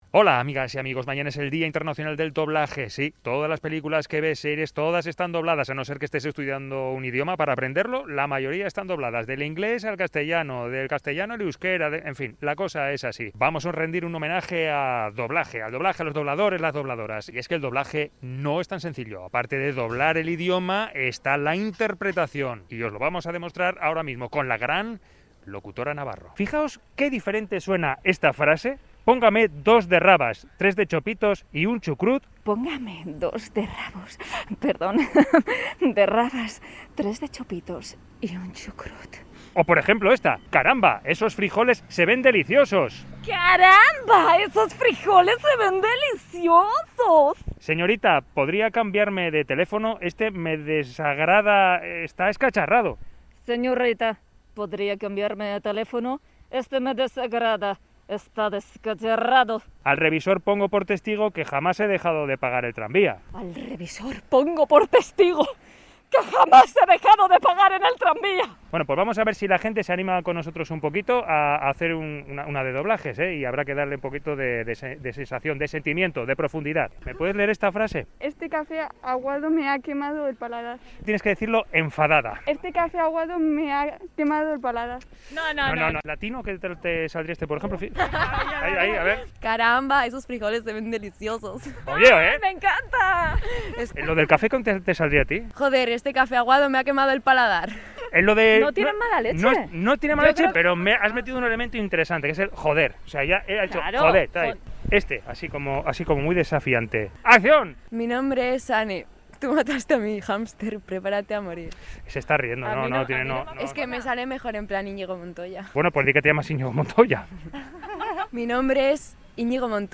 Audio: Reportaje: Improvisamos un doblaje en la calle
Improvisamos un doblaje en la calle